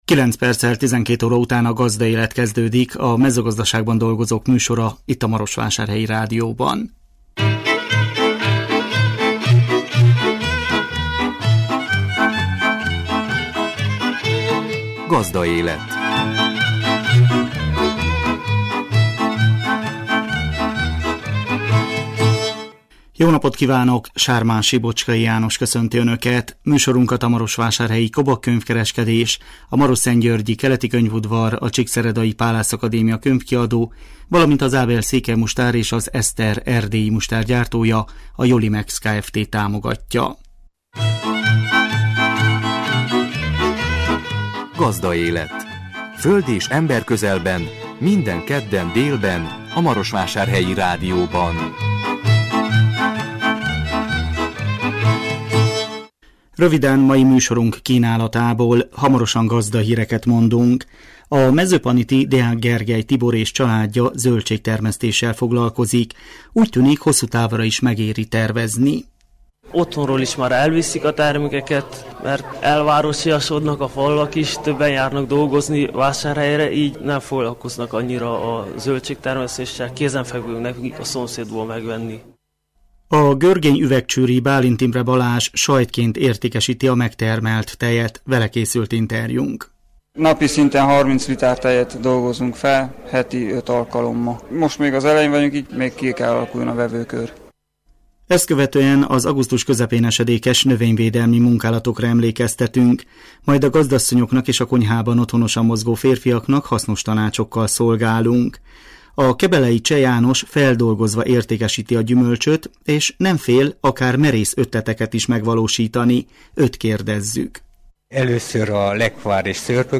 Vele készült interjúnk. Ezt követően az augusztus közepén esedékes növényvédelmi munkálatokra emlékeztetünk. Majd a gazdasszonyoknak és a konyhában otthonosan mozgó férfiaknak hasznos tanácsokkal szolgálunk.